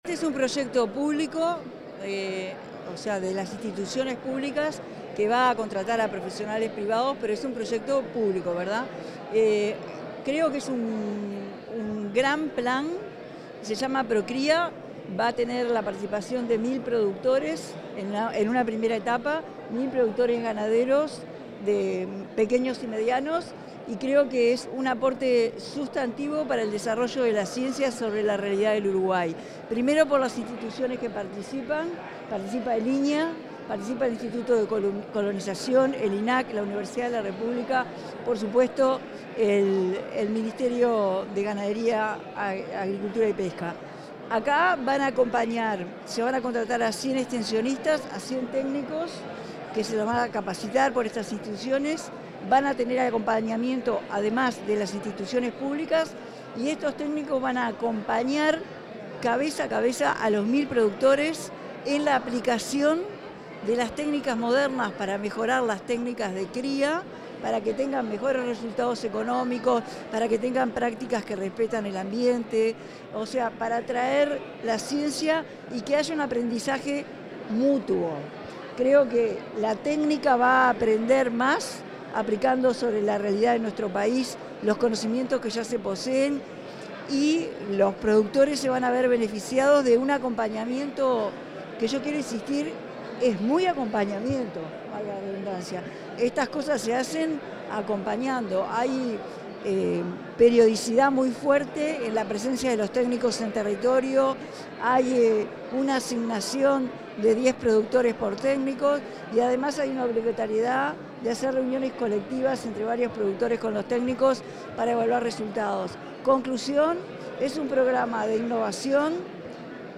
Declaraciones de la presidenta en ejercicio, Carolina Cosse, en lanzamiento de programa Procría
Declaraciones de la presidenta en ejercicio, Carolina Cosse, en lanzamiento de programa Procría 02/07/2025 Compartir Facebook X Copiar enlace WhatsApp LinkedIn Tras el lanzamiento del Programa de Innovación para una Ganadería de Cría Sostenible (Procría), del Ministerio de Ganadería, Agricultura y Pesca, la presidenta de la República en ejercicio, Carolina Cosse, realizó declaraciones a la prensa.